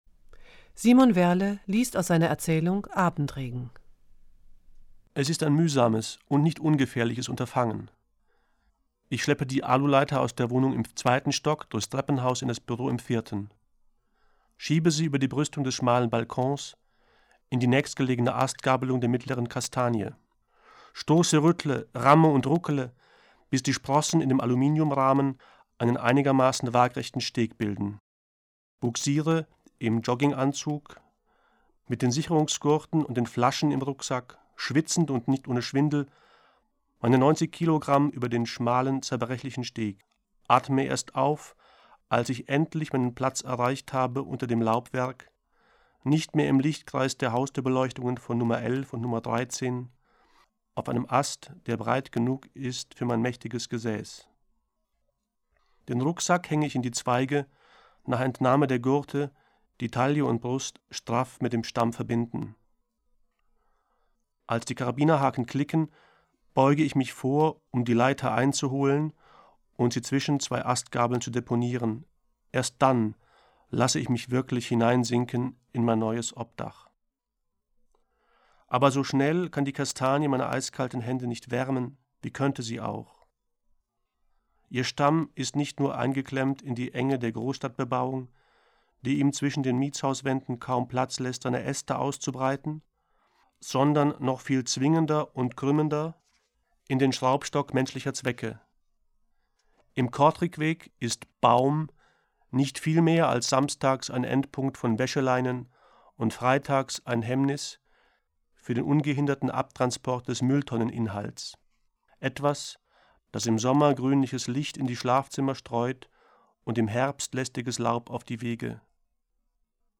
Es umfasst 40 CDs, auf denen insgesamt 573 Lesungen enthalten sind.